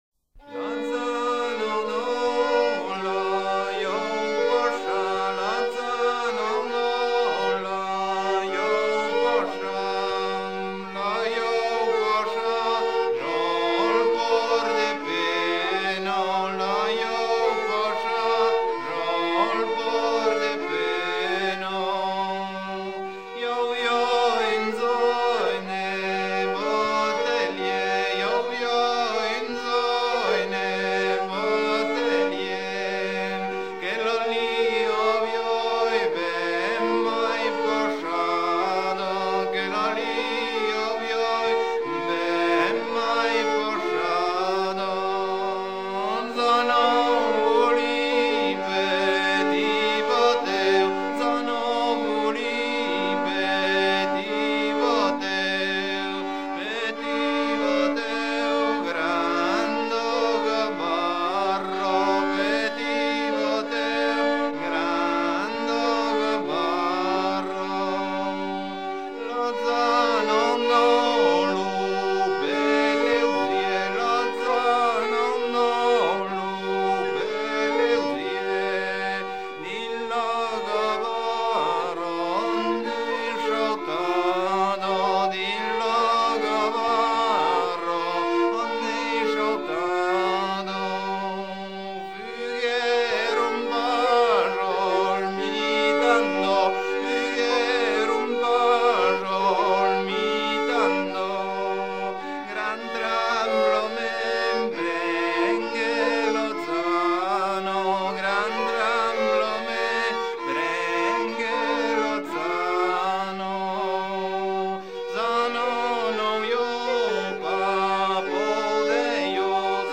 Version recueillie vers 1980
Genre strophique
Chants de mariniers